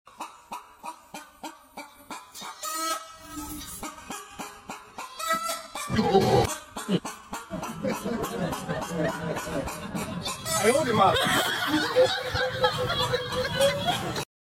funny video of chicken sounds sound effects free download